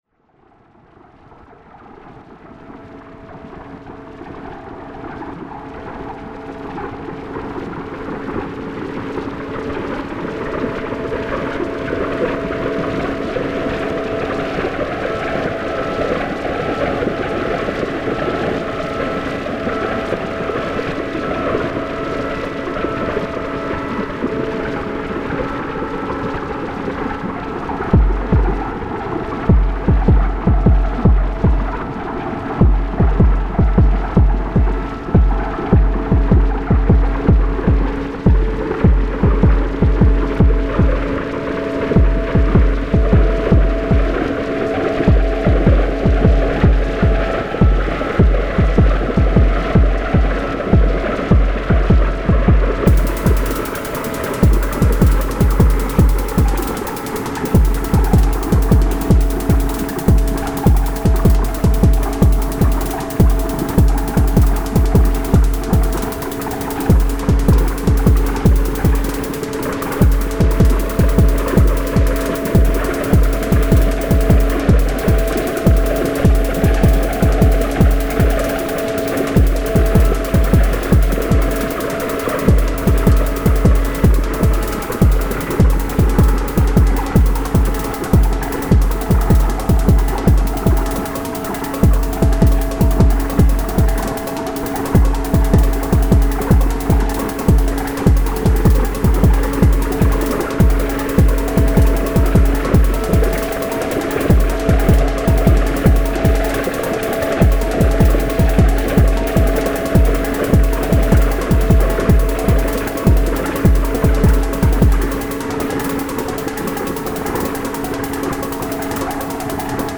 synthatik noise beat
Great timbres and atmosphere. Like this noisy drony broken beat.